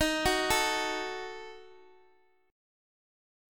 D#dim chord